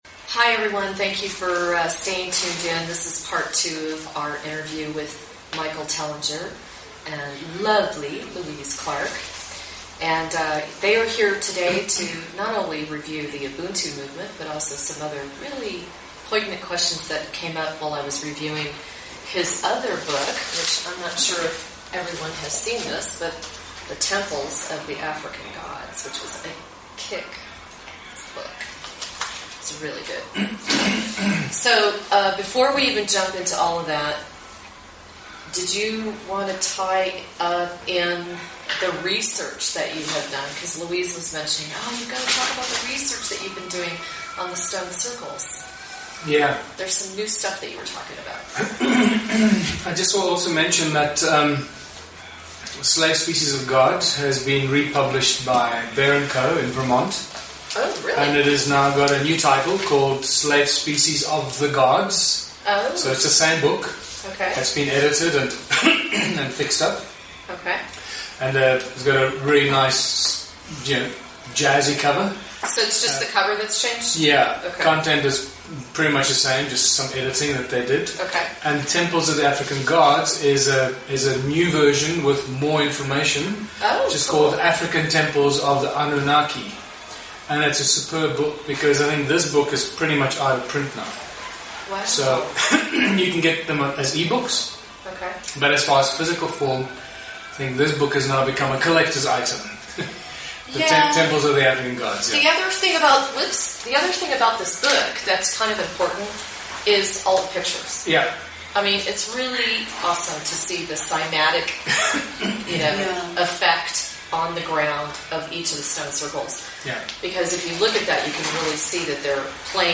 Talk Show Episode, Audio Podcast, Galactic Connection: What Do Ancient Ruins, Stone Circles, Gold Mining and Humanity's History All Have in Common?